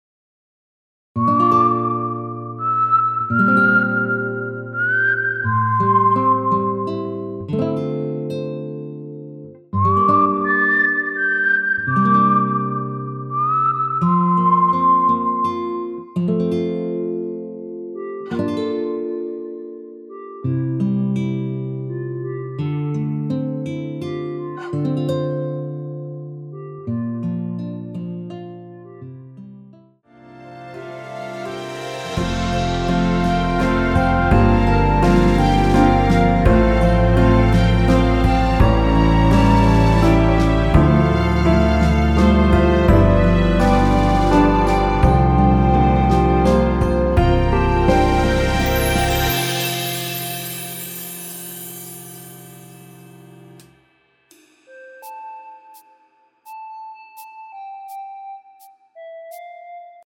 무반주 구간 들어가는 부분과 박자 맞출수 있게 쉐이커로 박자 넣어 놓았습니다.(미리듣기 확인)
원키에서(-3)내린 멜로디 포함된 MR입니다.(미리듣기 확인)
앞부분30초, 뒷부분30초씩 편집해서 올려 드리고 있습니다.